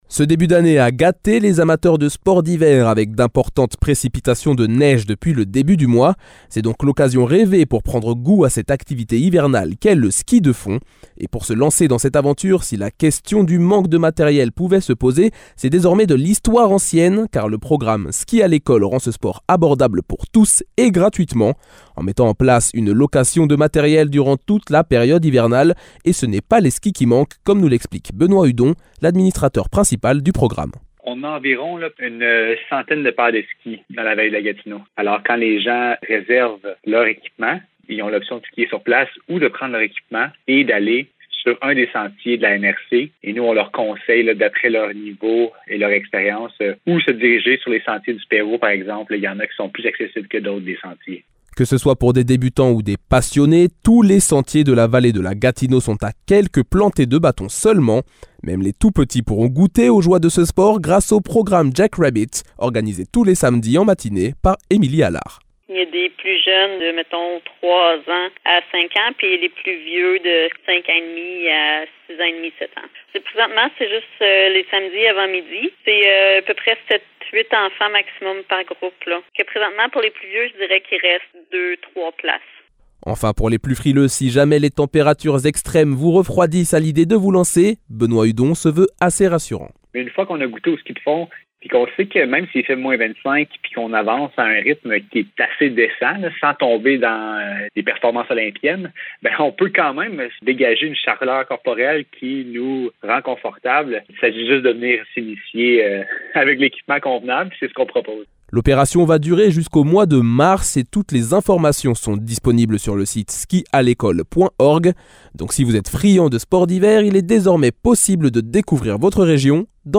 Épisode [Reportage] Programme « ski à l’école » - 28 janvier 2022